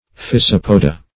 Physopoda \Phy*sop"o*da\, n. pl. [NL., fr. Gr. fy^sa a bellows +